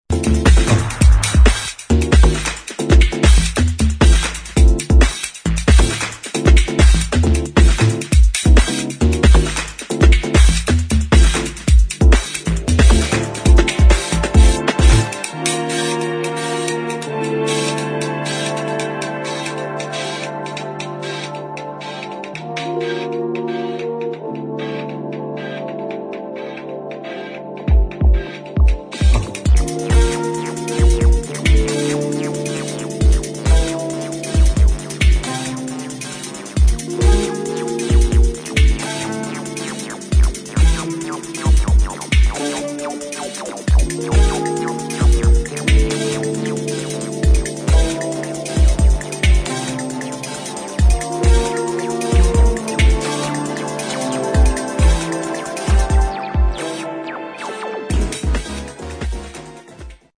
[ TECHNO / BROKEN BEAT / ELECTRONIC ]